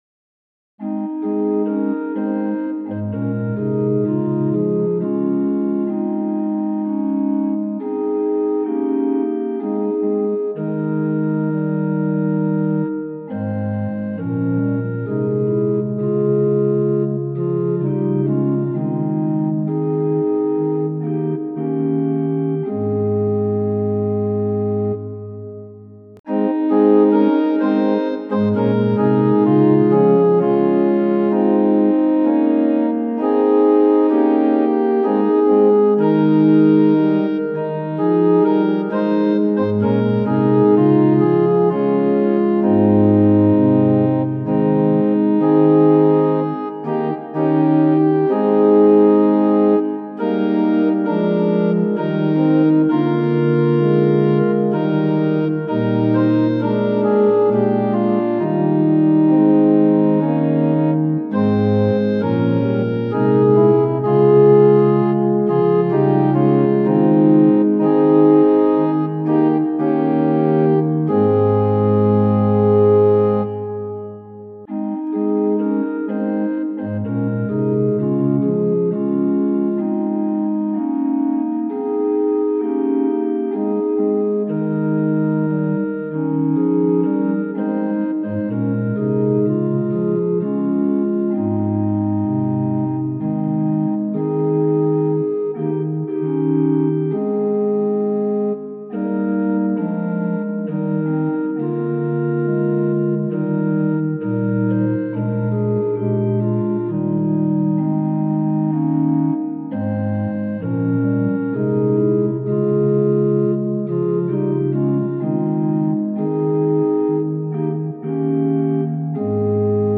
♪賛美用オルガン伴奏音源：
・柔らかい音色)部分は前奏です
・はっきりした音色になったら歌い始めます
・節により音色が変わる場合があります
・間奏は含まれていません
Tonality = As
Pitch = 440
Temperament = Equal